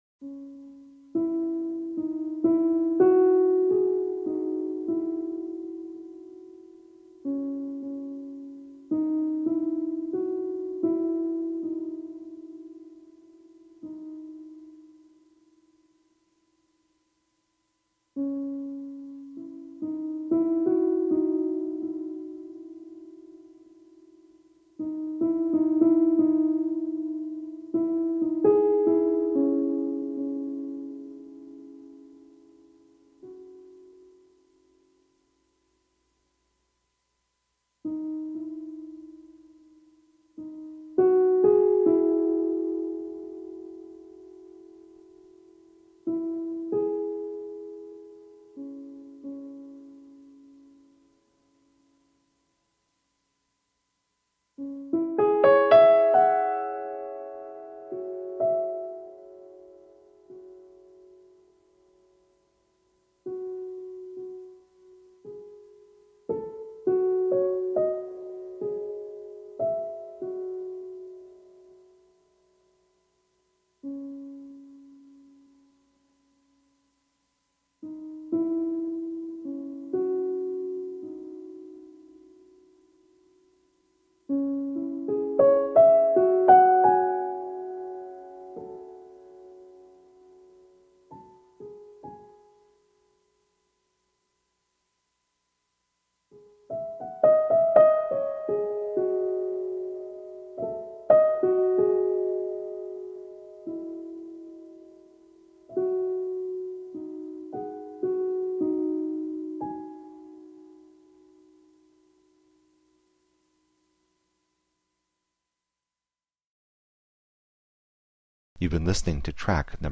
Minimalist piano with slashes of ambient electronics.